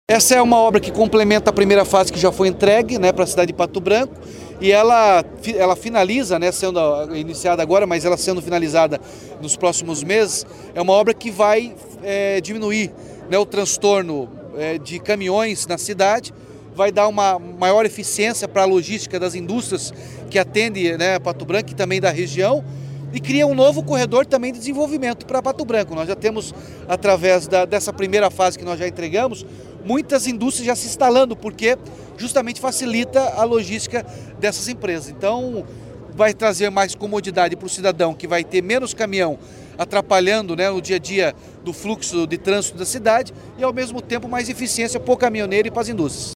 Sonora do governador Ratinho Junior sobre o início das obras da nova etapa do Contorno de Pato Branco